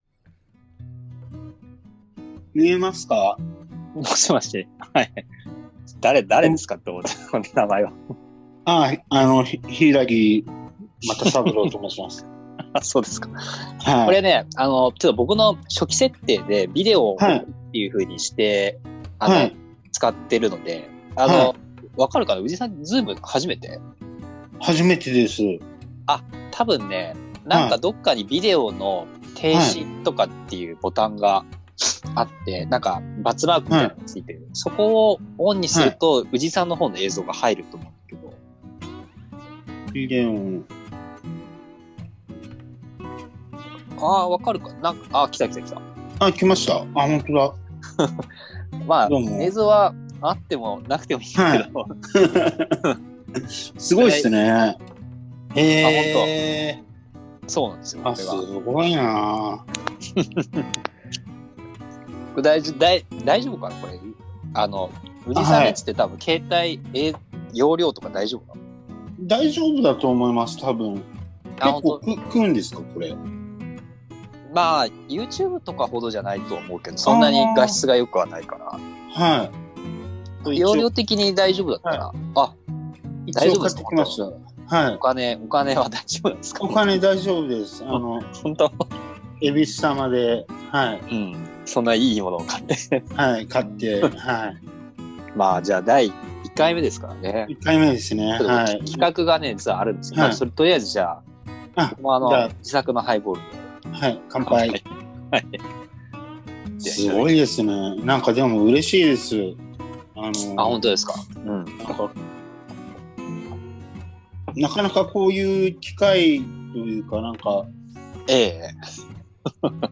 第１夜 ① オープニングトーク「こんなこと始めちゃって大丈夫なんですか？」